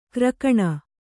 ♪ krakaṇa